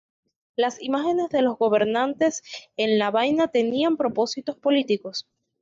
Pronounced as (IPA) /ˈbaina/